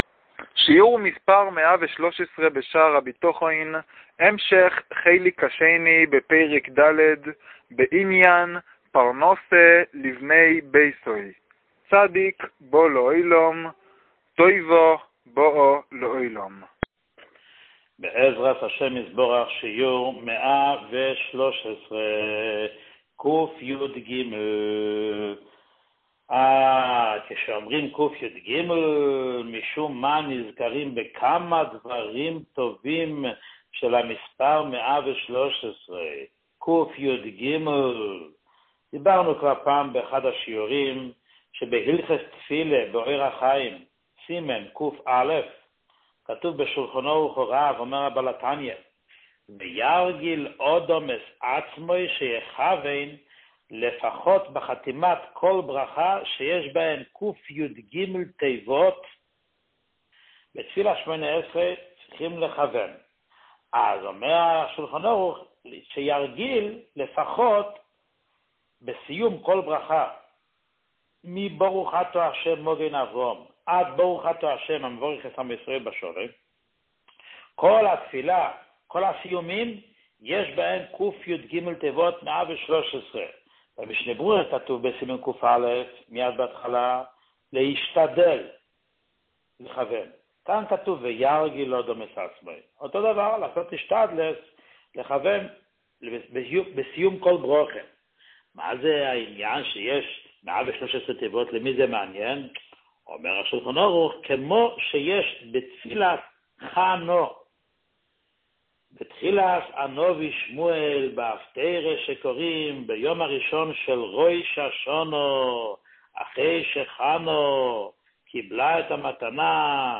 שיעור 113